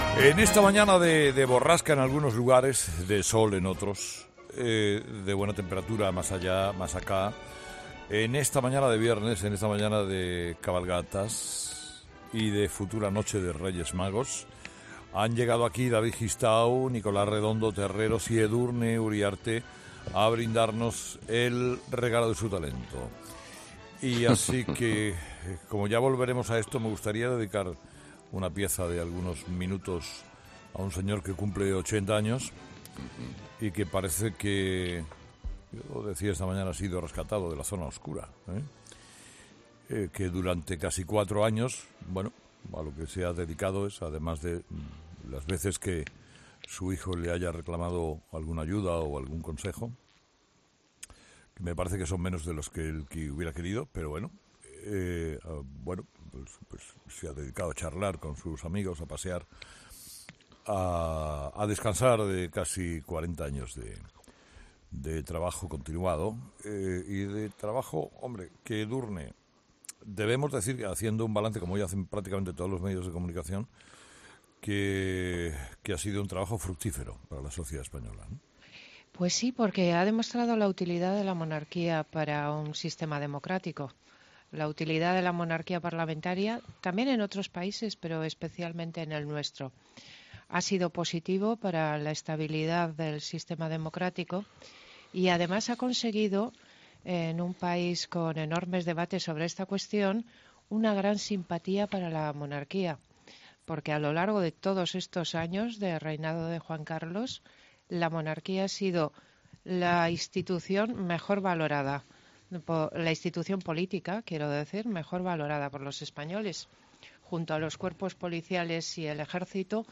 La tertulia de 'Herrera en COPE', con David Gistau, Nicolás Redondo Terreros y Edurne Uriarte, analiza la figura del Rey Juan Carlos I en su 80 cumpleaños.